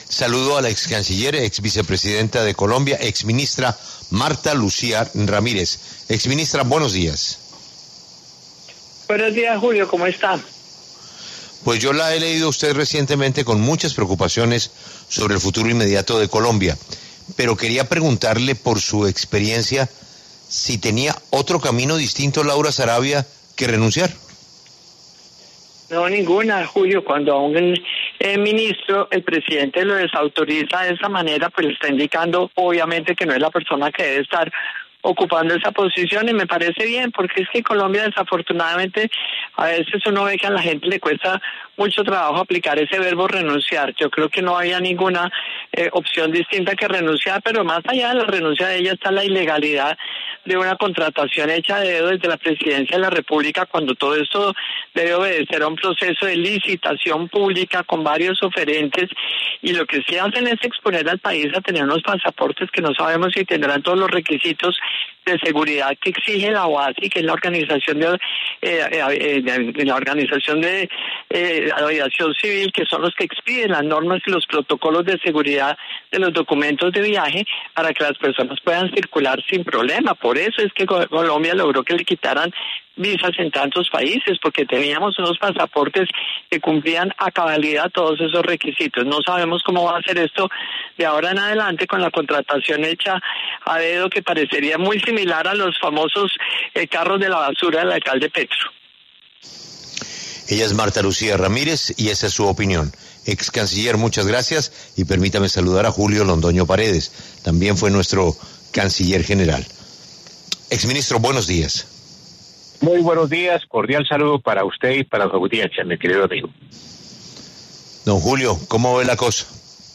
Marta Lucía Martínez y Julio Londoño Paredes hablaron en La W sobre los motivos que llevaron a Laura Sarabia a renunciar a su cargo.